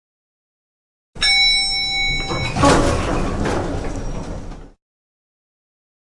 Download Elevator sound effect for free.
Elevator